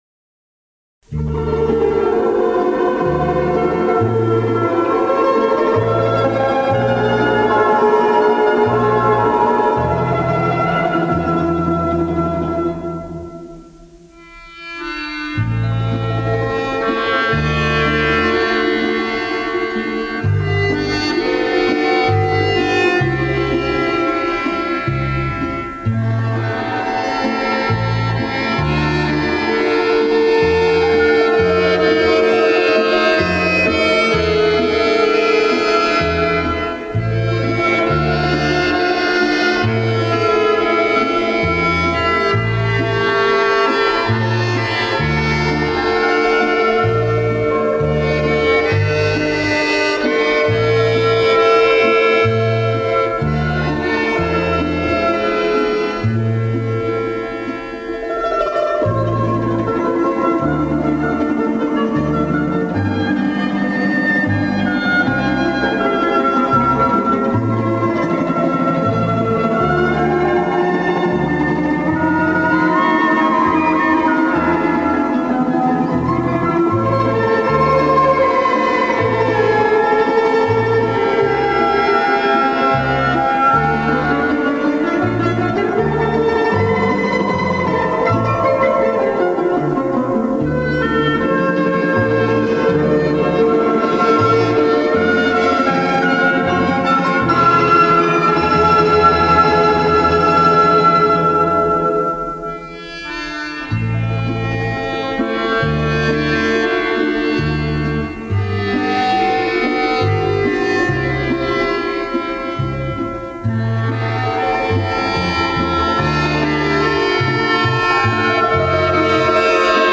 оркестр народных инструментов